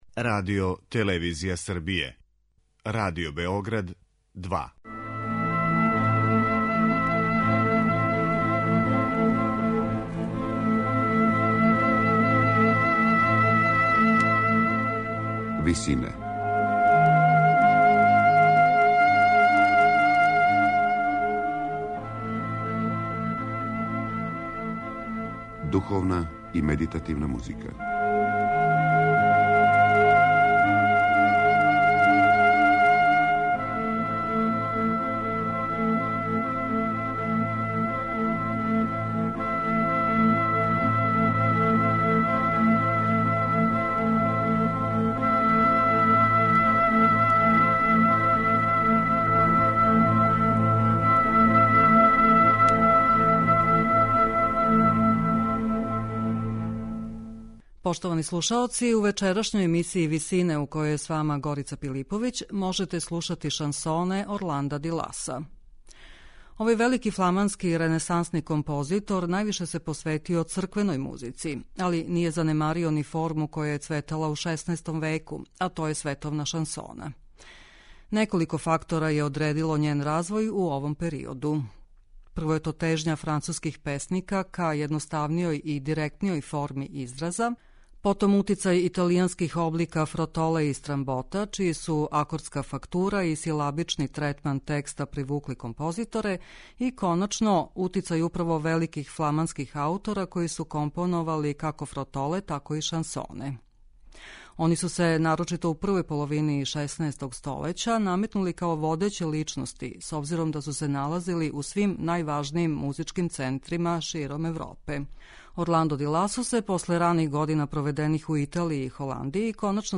Слушаћете шансоне Орланда ди Ласа
шансоне великог фламанског ренесансног композитора